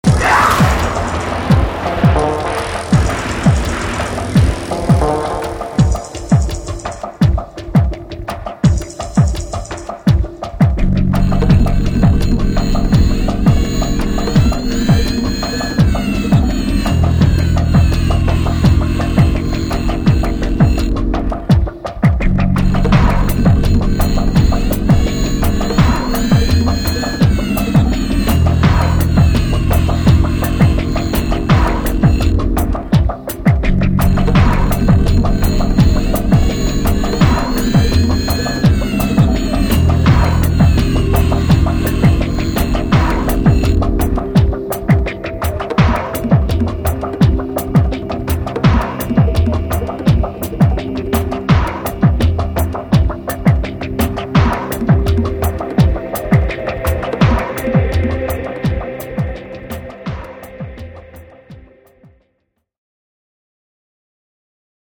electro-industrial